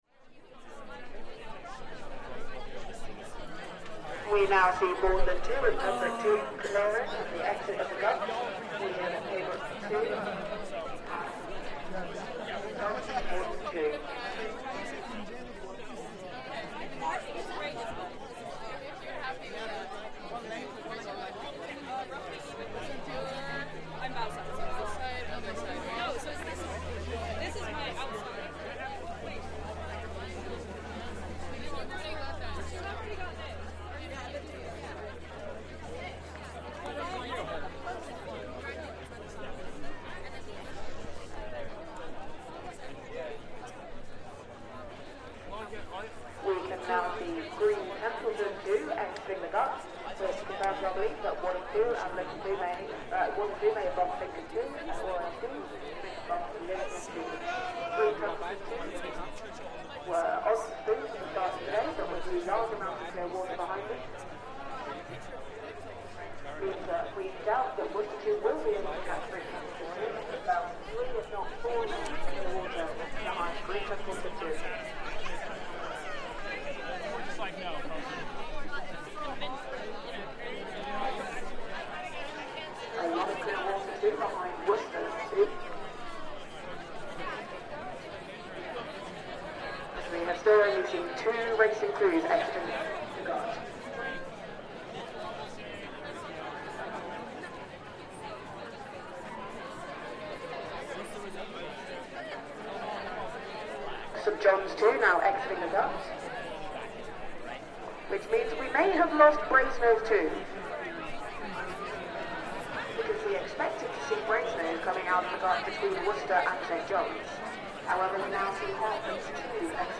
Soundscape from the traditional Oxford University rowing competition, Eights Week, held in May each year.
You can hear commentary on race preparation, advice for boating crews and race in progress, crowds chattering and cheering and the atmosphere of the event.